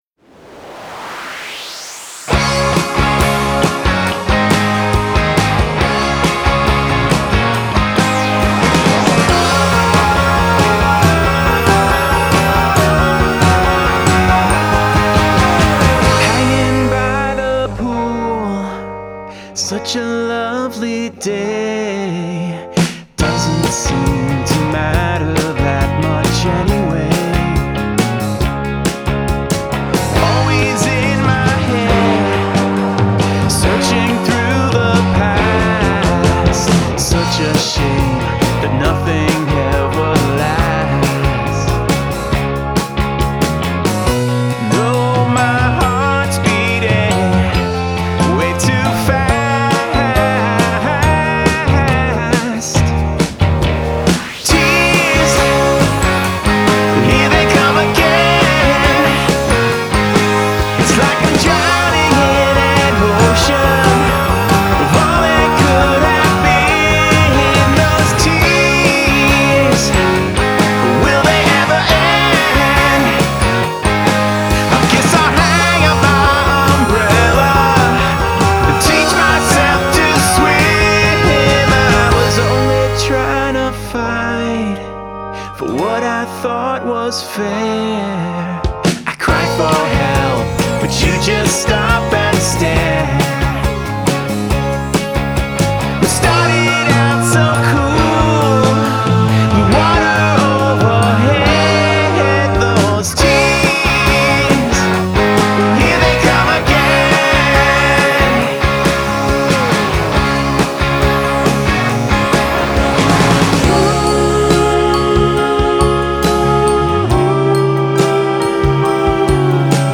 which channels a bit of ELO and 10CC